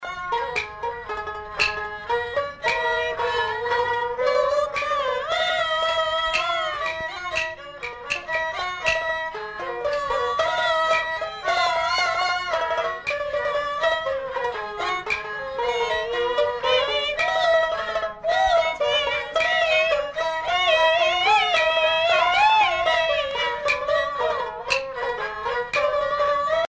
voix de fausset, d'un théâtre chinois
Pièce musicale éditée